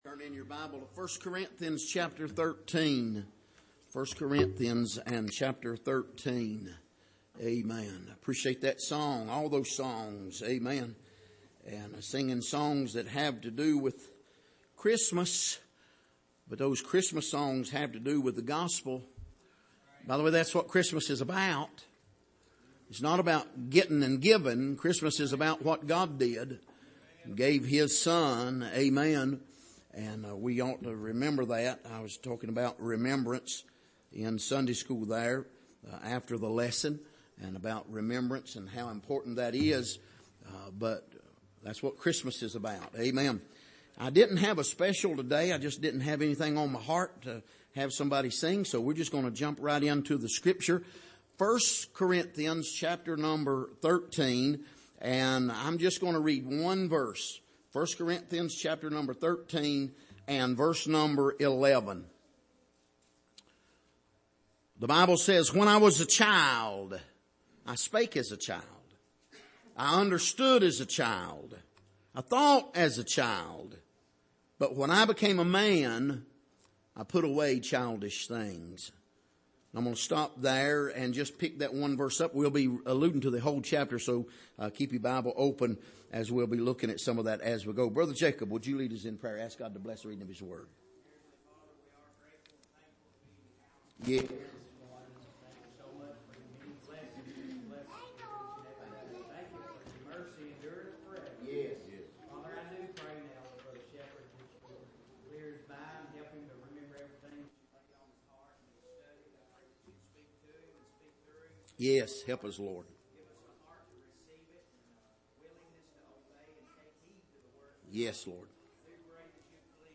Miscellaneous Passage: 1 Corinthians 13:11 Service: Sunday Morning